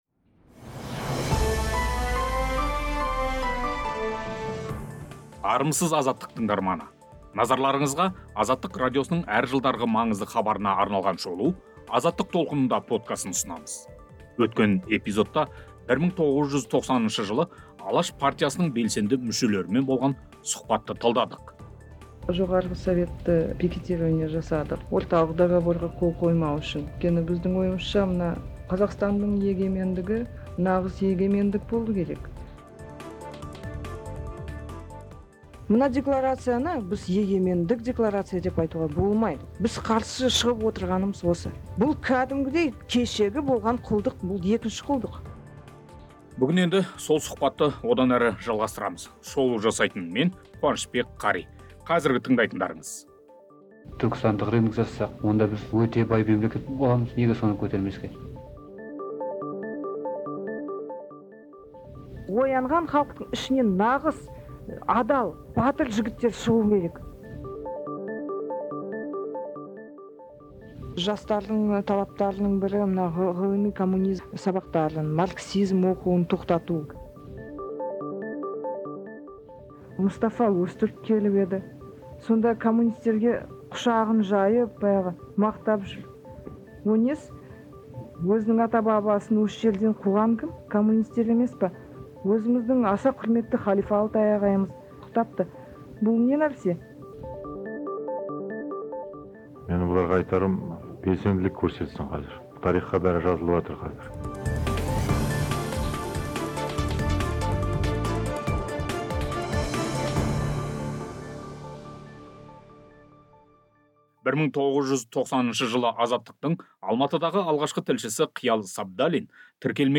Алматы мен Киевтегі митингілердің соңы не болды? Тіркелмеген "Алаш" партиясының мүшелері Азаттықпен сұхбатында тоқсаныншы жылдарғы өздерінің тұжырым, жоспары, жастар белсенділігі және биліктің қысым тәсілдері жайлы айтады.